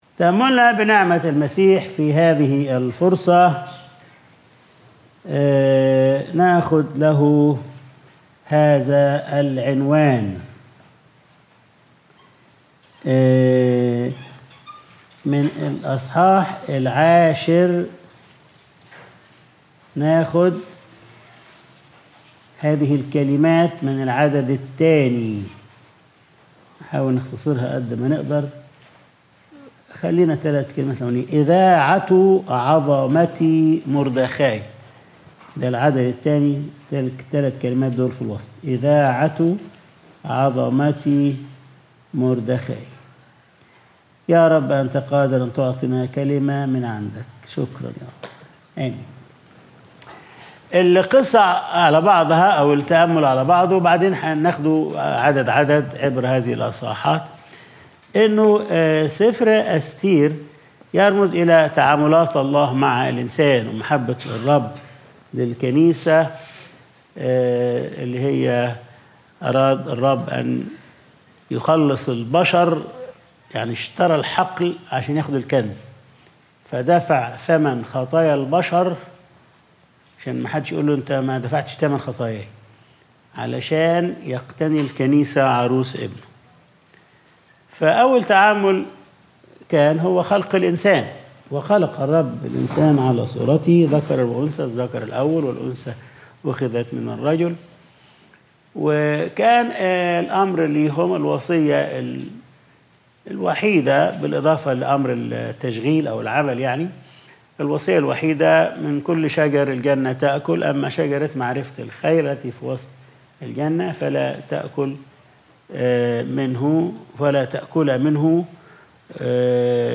Sunday Service | إذاعة عظمة مُردخاي